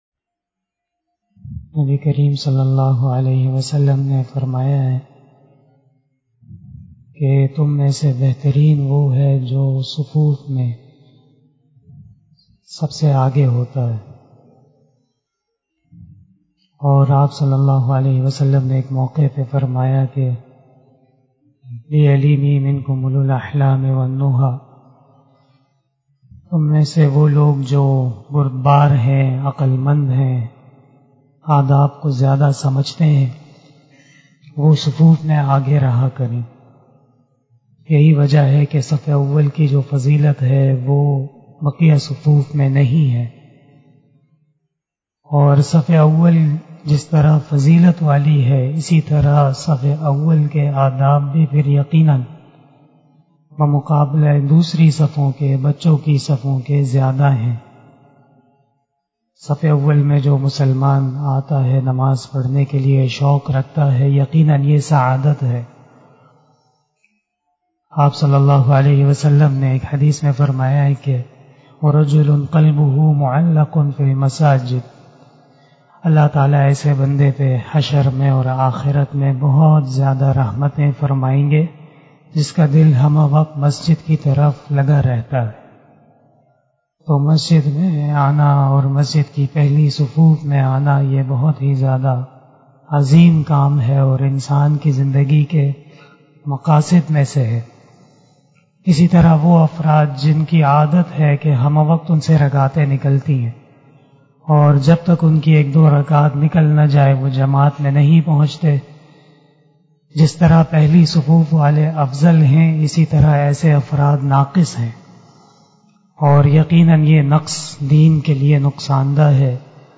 025 After Asar Namaz Bayan 05 June 2021 ( 24 Shawwal 1442HJ) saturday